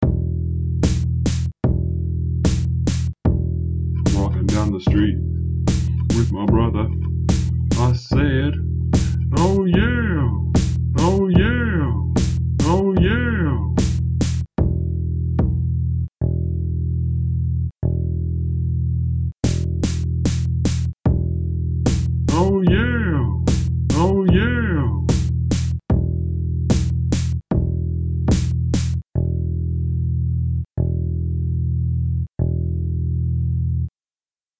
Oh Yeah! - I used Audacity to create this little song.